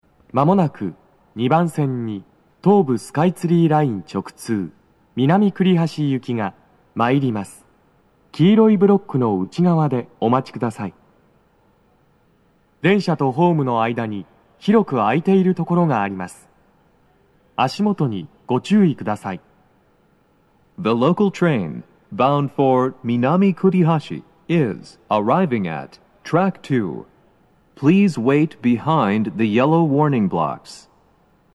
スピーカー種類 BOSE天井型
2番線 北千住・南栗橋方面 接近放送 【男声
接近放送3
hminowa2sekkinminamikurihashi.mp3